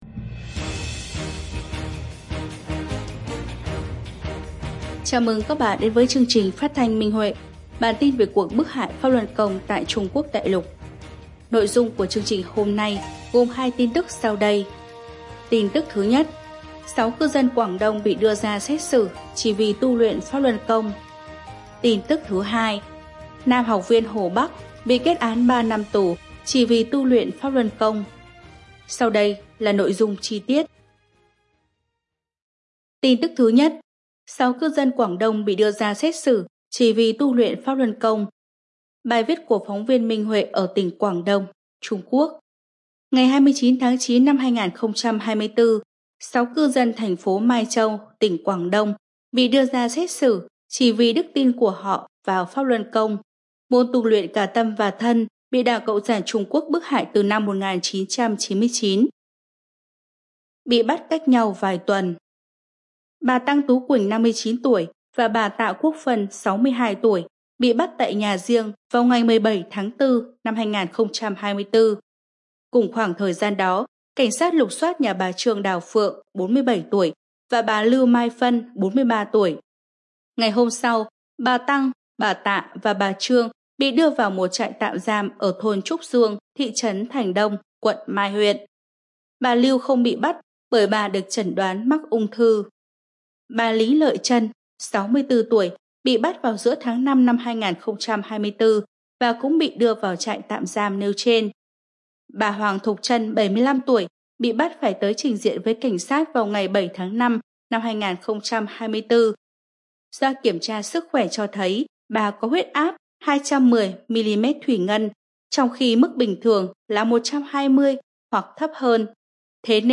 Chương trình phát thanh số 149: Tin tức Pháp Luân Đại Pháp tại Đại Lục – Ngày 24/10/2024